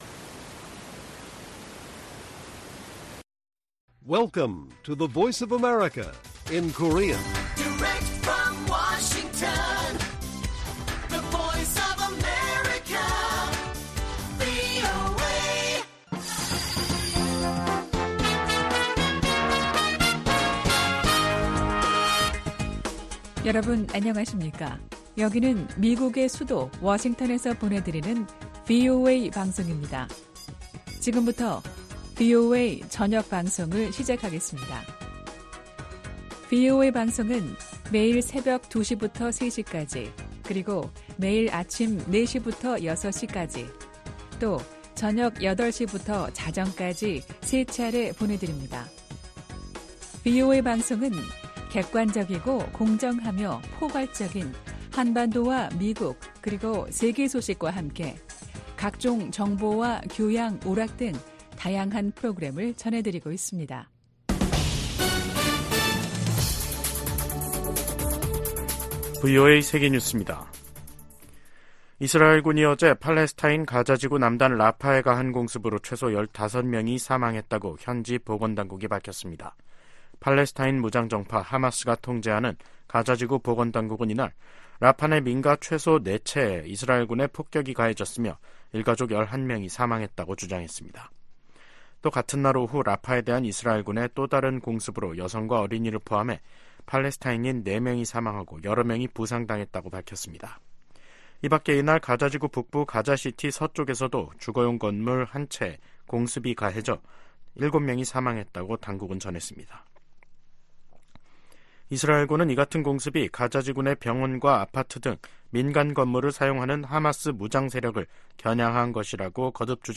VOA 한국어 간판 뉴스 프로그램 '뉴스 투데이', 2024년 3월 28일 1부 방송입니다. 미국과 한국이 공동으로 북한 국적자 6명과 외국업체 2곳에 대한 제재를 단행했습니다. 북한이 최근 원심분리기 시설을 확장하고 있는 것으로 보인다는 보도가 나온 가운데 미국 정부는 위험 감소 등 북한과 논의할 것이 많다는 입장을 밝혔습니다. 중국이 미일 동맹 격상 움직임에 관해, 국가 간 군사협력이 제3자를 표적으로 삼아선 안 된다고 밝혔습니다.